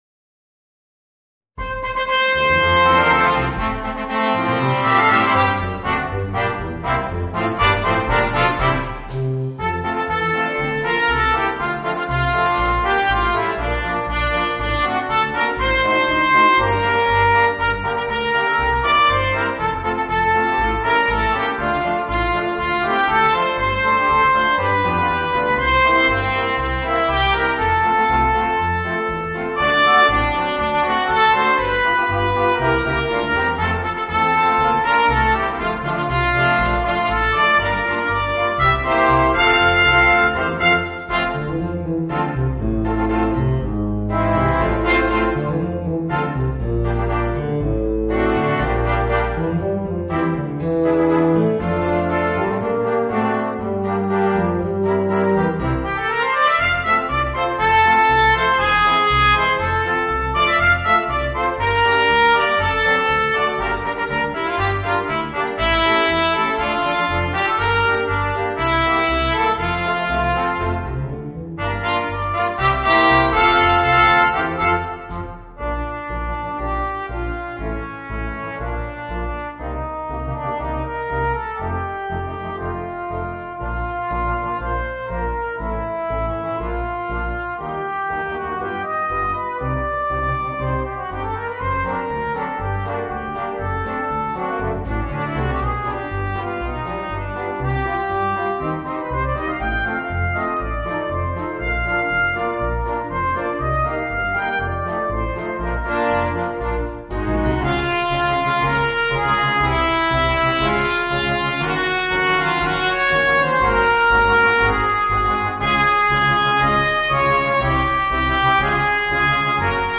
Voicing: 2 Trumpets, 3 Trombones and Drums